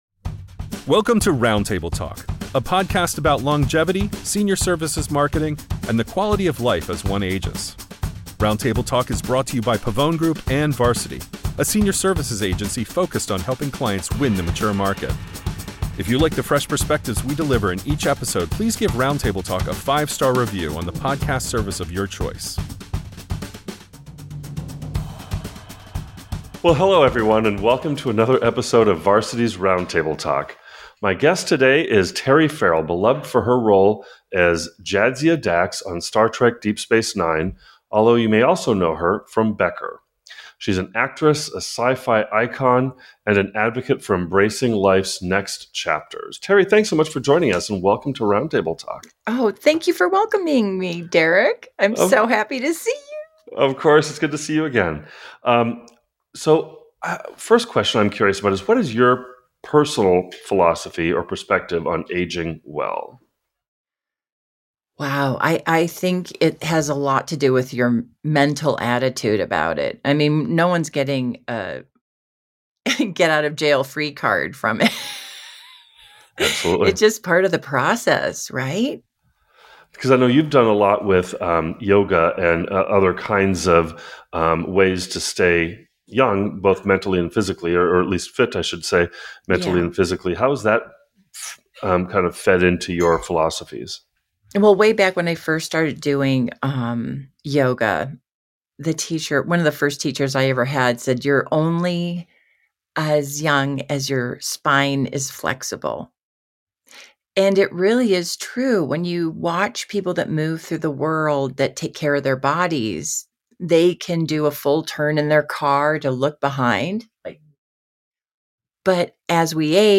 On this episode of Varsity’s Roundtable Talk, we sit down with Terry Farrell, acclaimed actress and sci-fi icon best known for her role as Jadzia Dax on Star Trek: Deep Space Nine and Reggie on Becker.